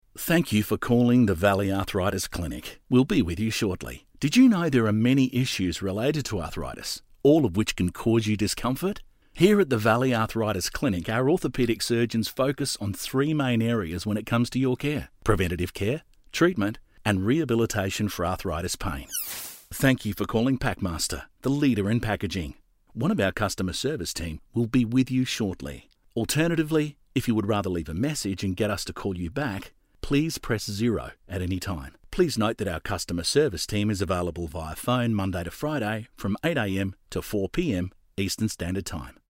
I guess you can call me ‘the everyday bloke’.
• On Hold